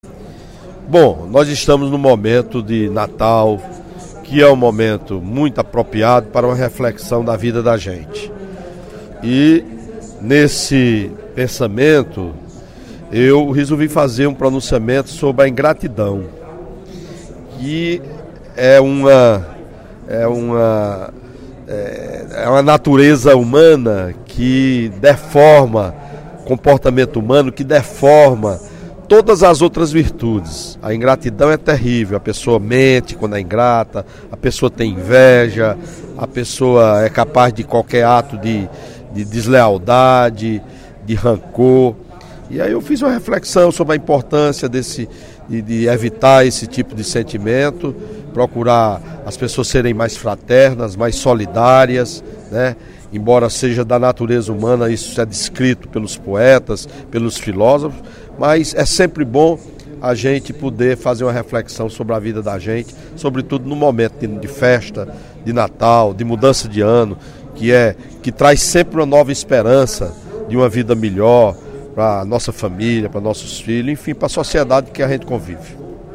O deputado Welington Landim (Pros), em pronunciamento no primeiro expediente da sessão plenária desta quinta-feira (18/12), aproveitou o período de final de ano e Natal para fazer uma reflexão sobre o significado da palavra gratidão e seu oposto, a ingratidão.